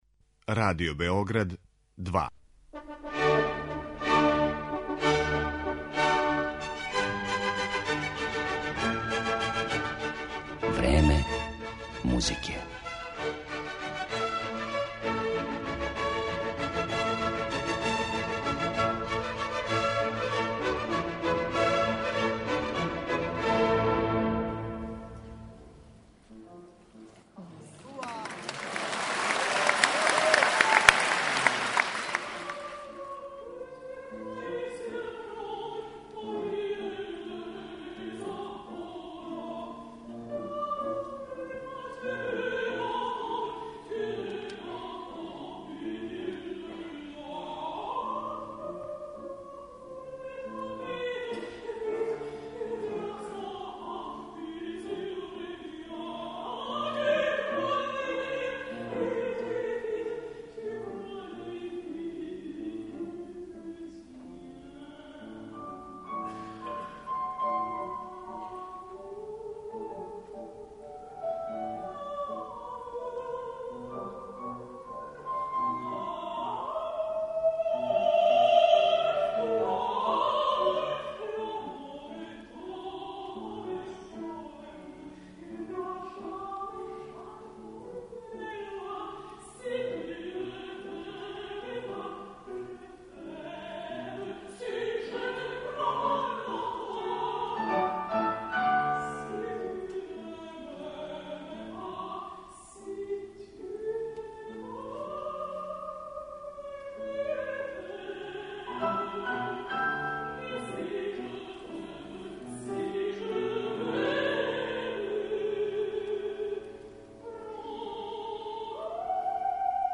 Oсим изабраних арија, можете чути и део интервјуа са славном летонском уметницом, који је заблежен након концерта.